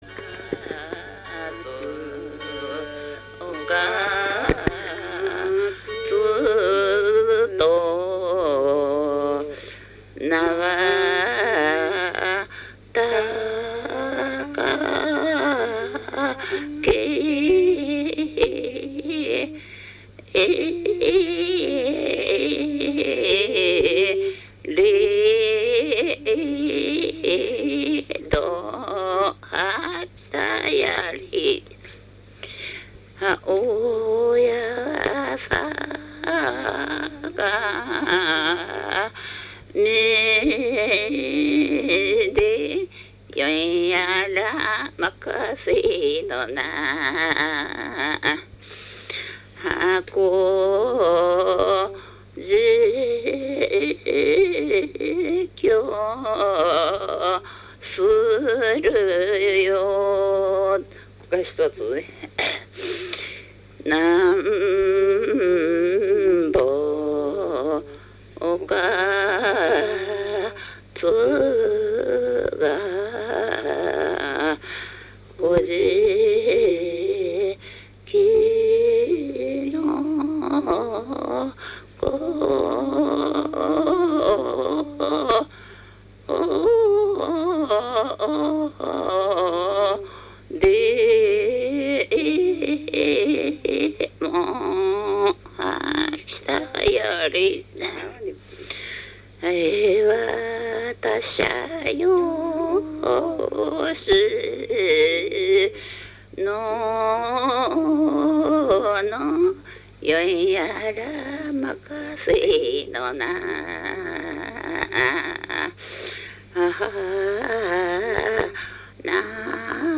おかつ節 座興歌